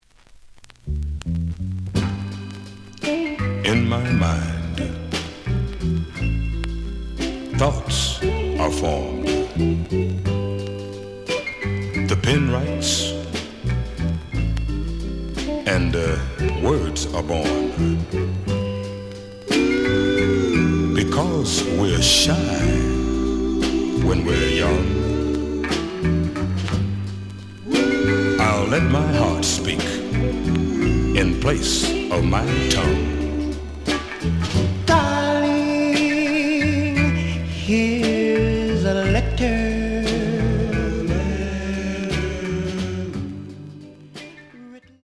The audio sample is recorded from the actual item.
●Genre: Soul, 60's Soul
Slight edge warp.